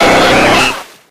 Cries
TENTACOOL.ogg